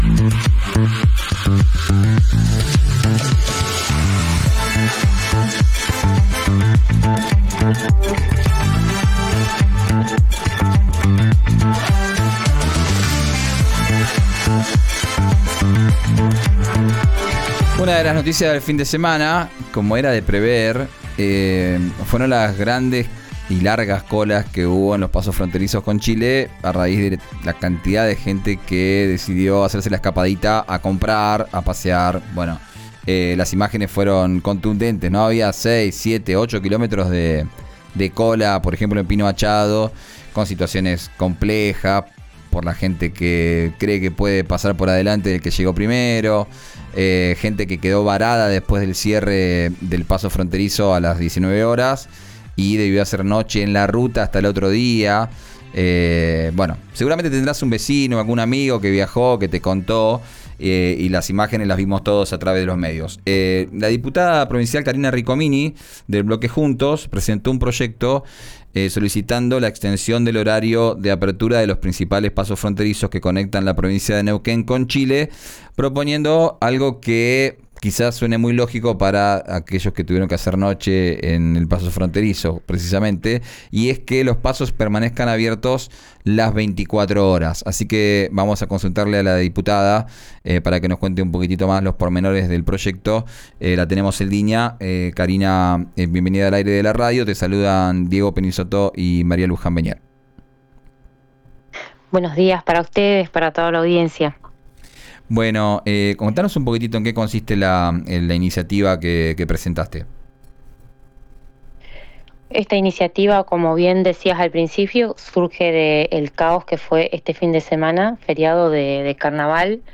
Escuchá a la diputada Carina Riccomini en RÍO NEGRO RADIO: